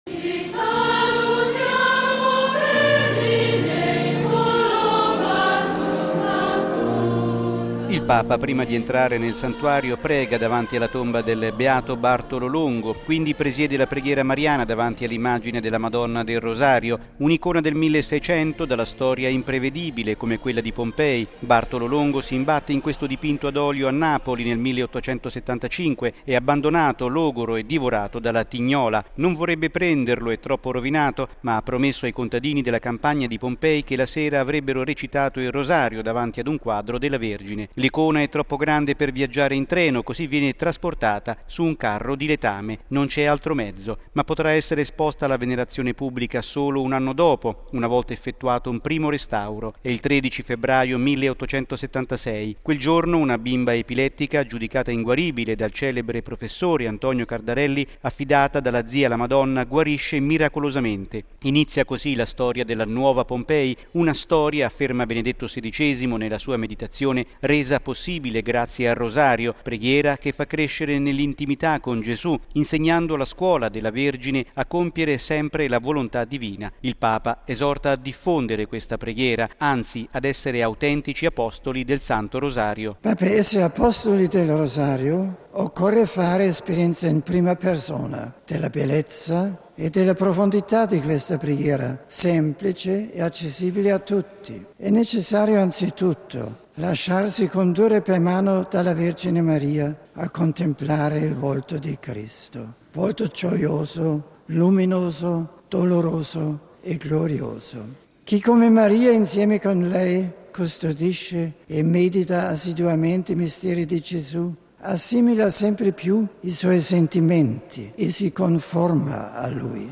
Particolarmente calorosa l’accoglienza dei tanti fedeli accorsi per pregare insieme al Successore di Pietro.
(Canto)